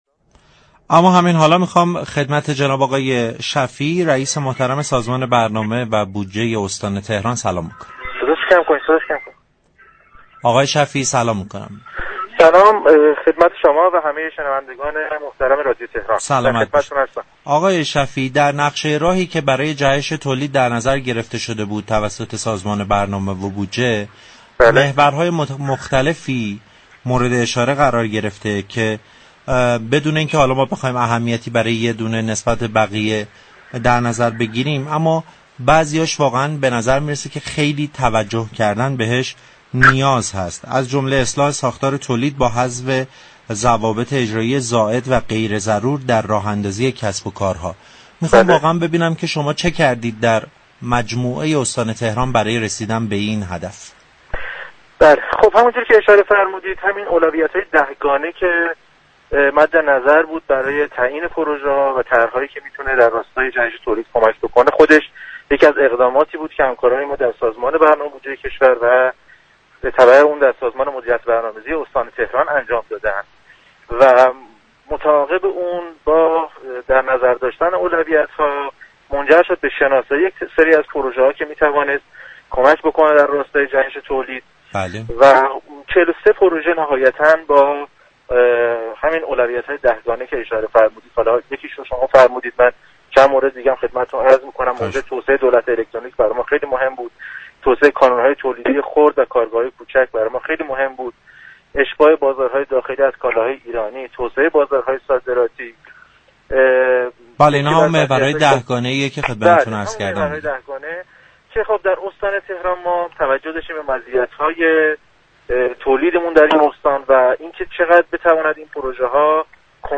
مسعود شفیعی، رئیس سازمان برنامه و بودجه استان تهران در گفتگو با رادیو تهران گفت: به كسب و كارهایی كه برای محیط زیست استان تهران آسیب وارد می كنند مجوز فعالیت نمی‌دهیم.